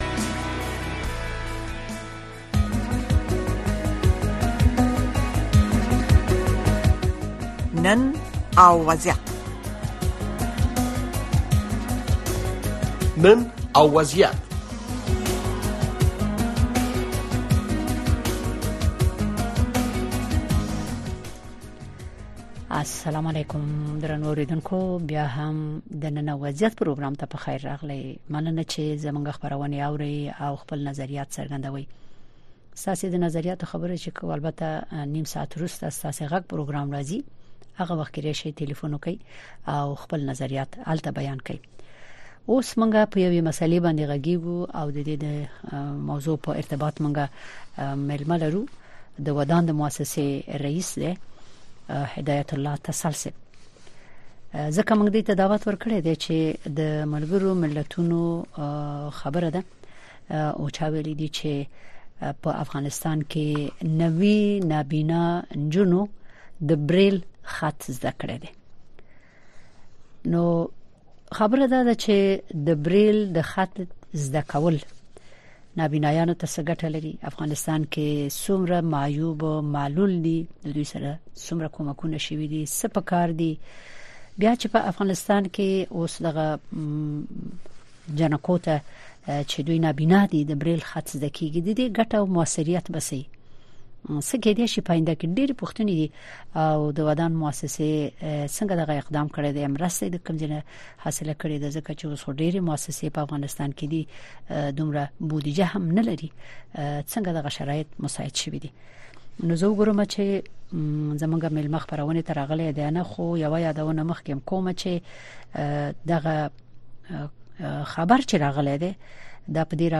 د نن او وضعیت په خپرونه کې د افغانستان د ټولنیز او فرهنګي وضعیت ارزونه ددې خپرونې له میلمنو څخه اورئ. دغه خپرونه هره شپه د ٩:۳۰ تر ۱۰:۰۰ پورې په ژوندۍ بڼه ستاسې غږ د اشنا رادیو د څپو او د امریکا غږ د سپوږمکۍ او ډیجیټلي خپرونو له لارې خپروي.